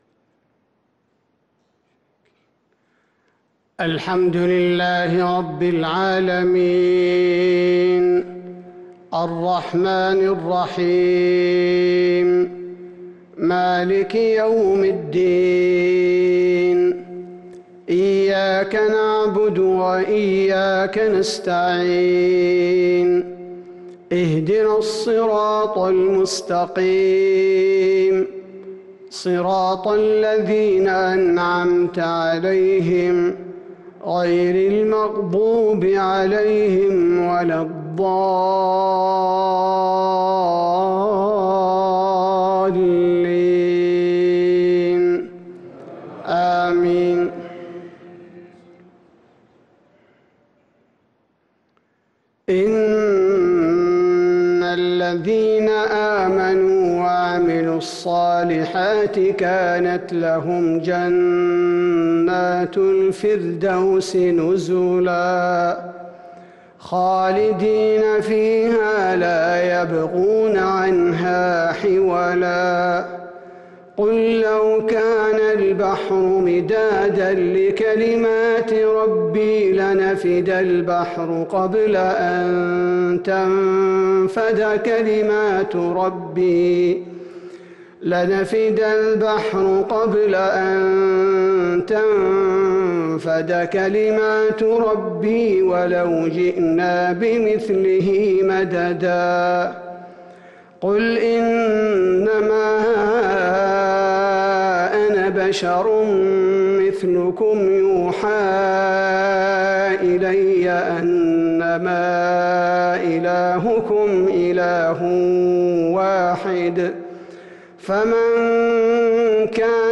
صلاة المغرب للقارئ عبدالباري الثبيتي 5 صفر 1443 هـ
تِلَاوَات الْحَرَمَيْن .